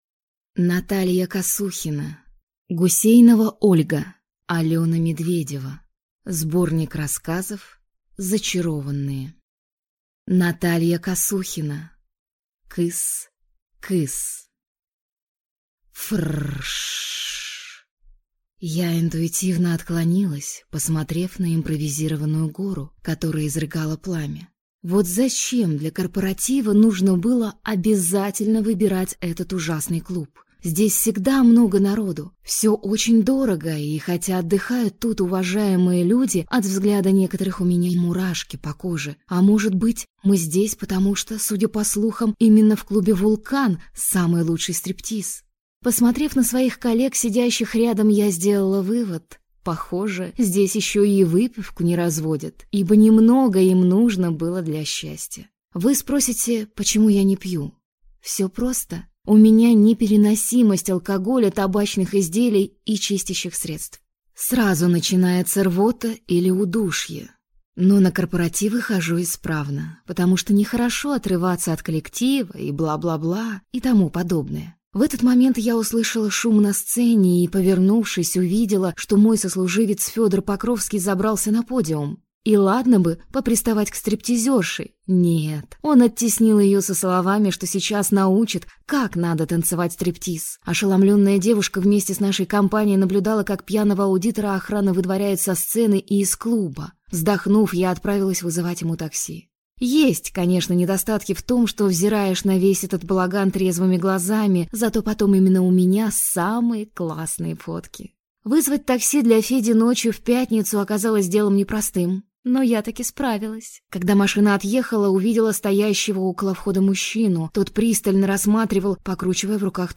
Аудиокнига Зачарованные | Библиотека аудиокниг